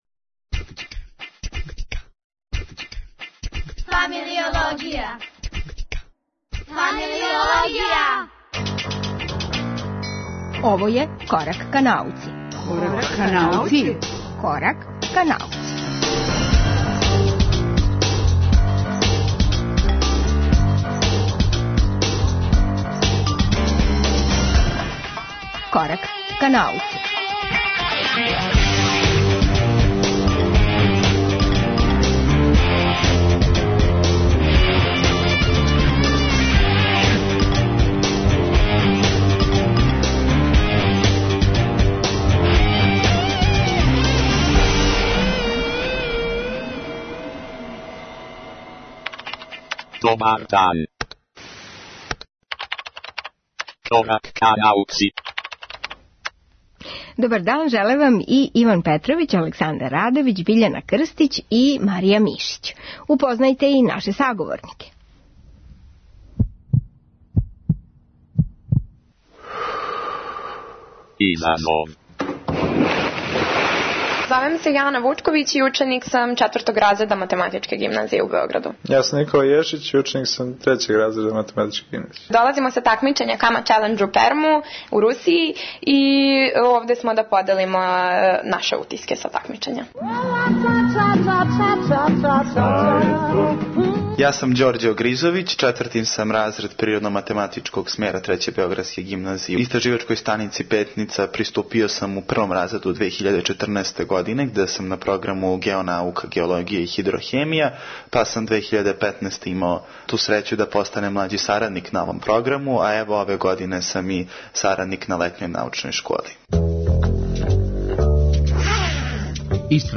Корачање ка науци доноси четири разговора у четири рубрике: Изазов, Један је Галоа, Отворена лабораторија, Хемија за сваки дан, као и Шест немогућих ствари пре ручка.